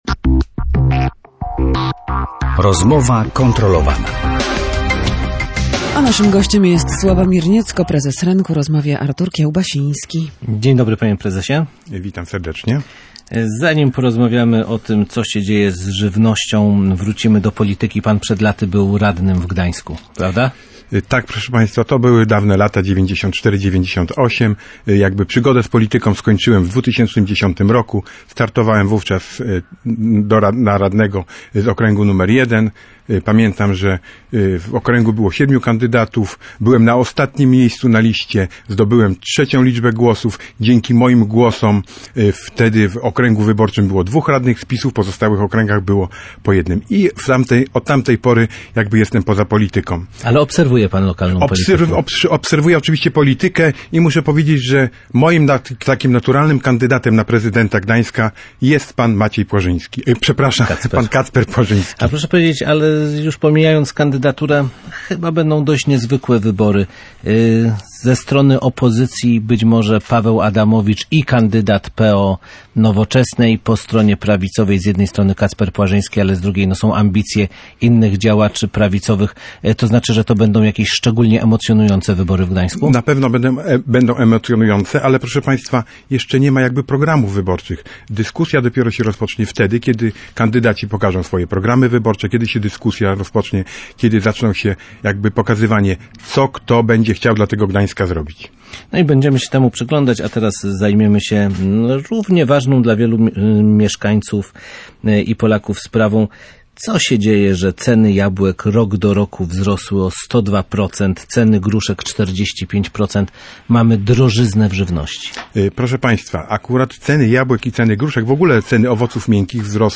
Pogoda wpływa na zbiory, a zbiory dyktują ceny na rynku. O wzroście cen owoców rozmawialiśmy na antenie Radia Gdańsk.